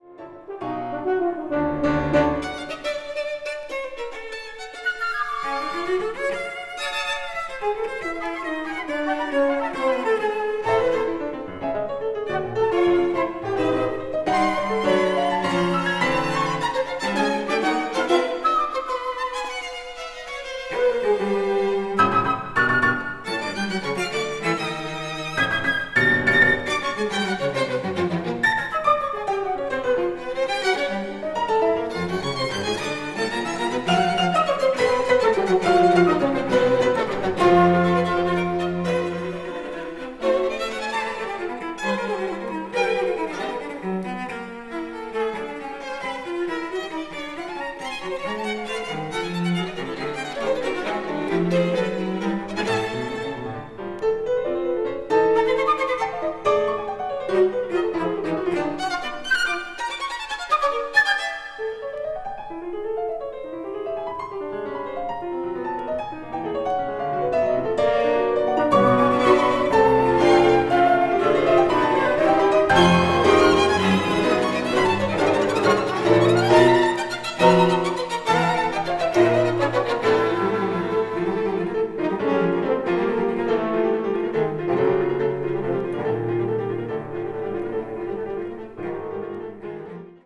presenting a modern classical approach.